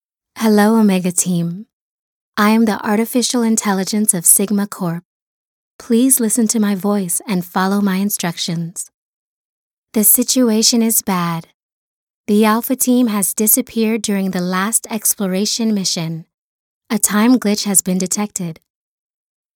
Voice Type:  Expressive, rich, conversational, with a touch of rasp.